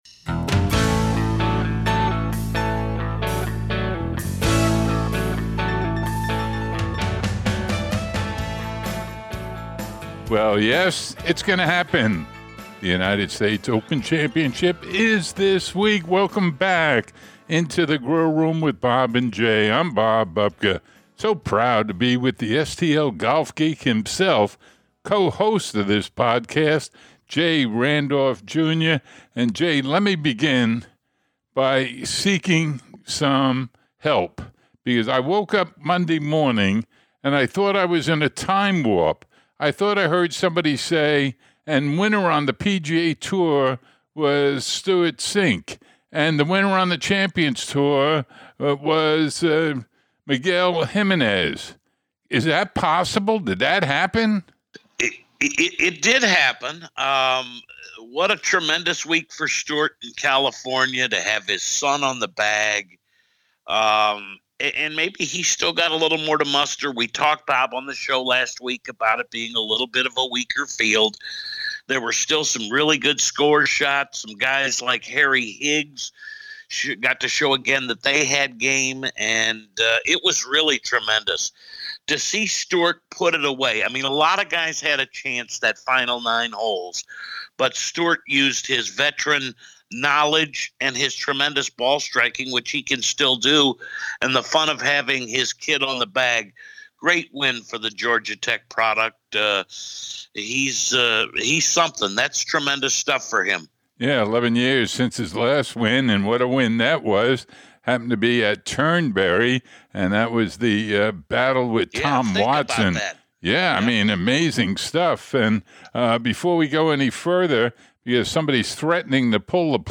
Audio insights from Cink, Tiger, Dustin Johnson, Bryson DeChambeau, and defending champion, Gary Woodland.